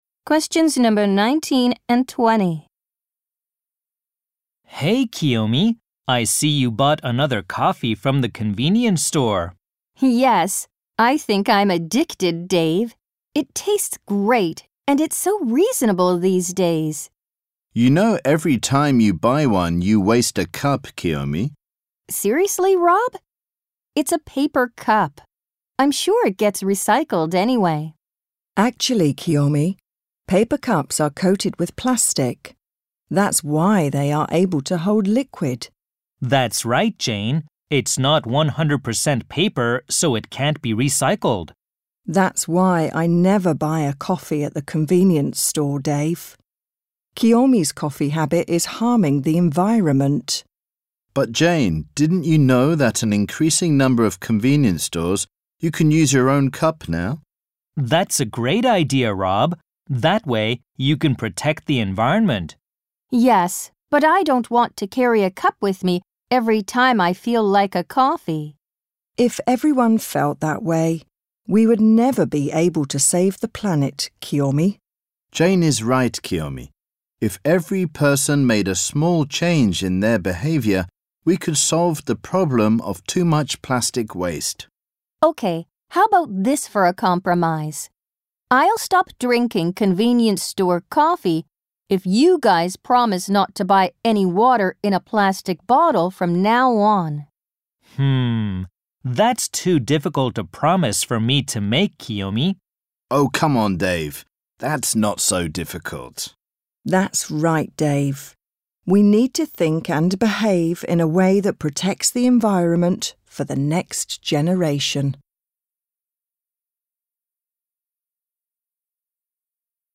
○共通テストの出題音声の大半を占める米英の話者の発話に慣れることを第一と考え，音声はアメリカ（北米）英語とイギリス英語で収録。
（新）第5問形式：【第14回】第3問　問17 （アメリカ（北米）英語+イギリス英語）